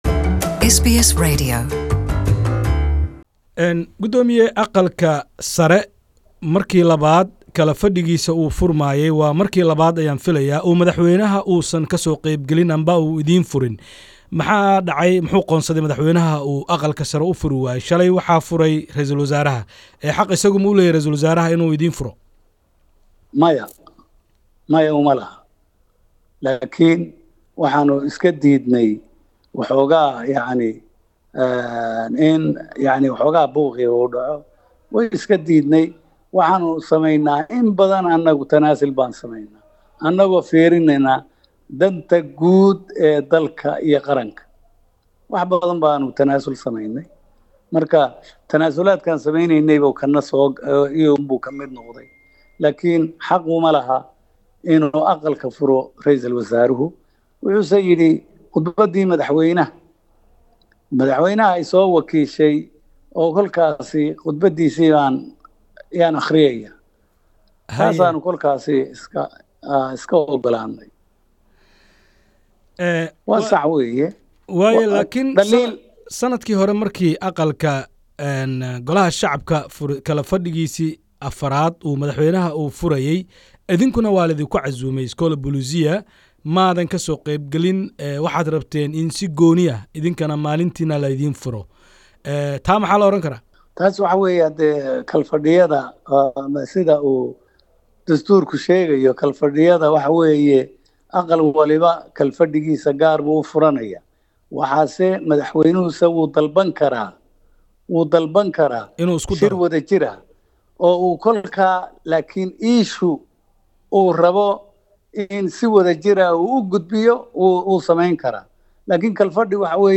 Interview: Speaker of Somali upper house, Abdi Hashi
Waraysi: Gudoomiyaha aqalka sare ee Soomaaliya, Cabdi Hashi